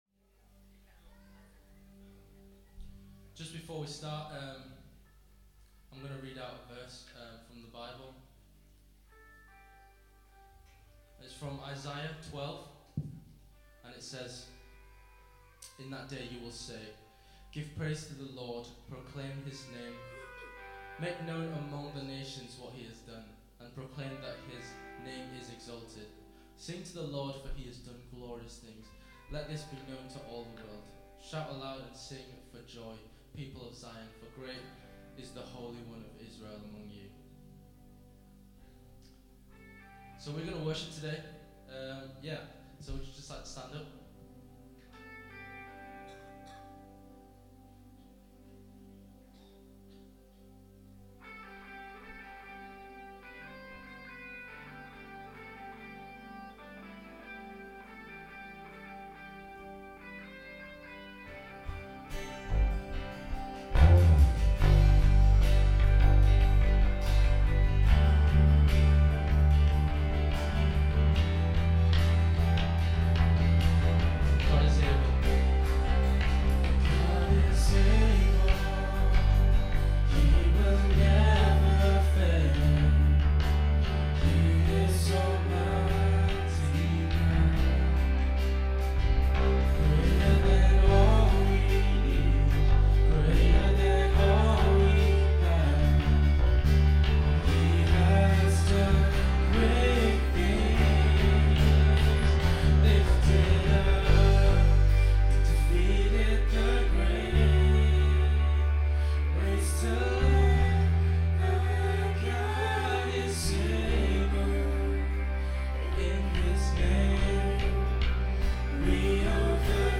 Worship February 8, 2015 – Birmingham Chinese Evangelical Church
Lead/Guitar
Vocals
E.Guitar
Drums
Bass